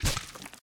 flop2.ogg